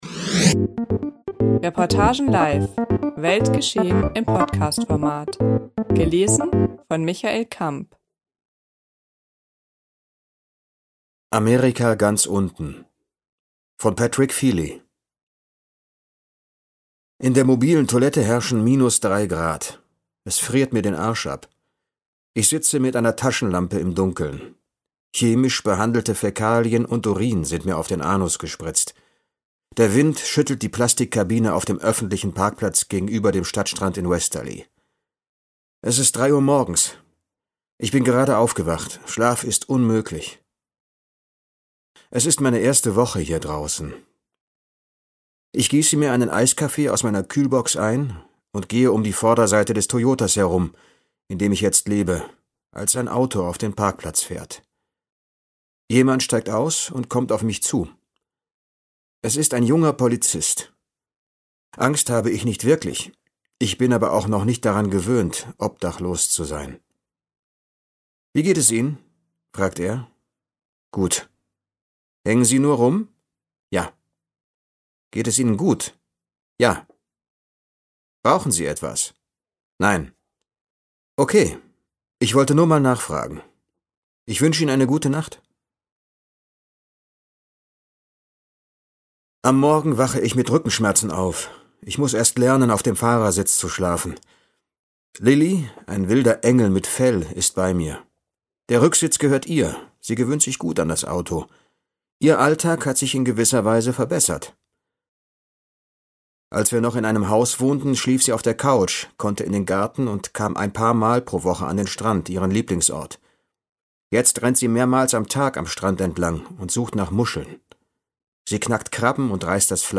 Amerika ganz unten ~ Podcast Reportagen live.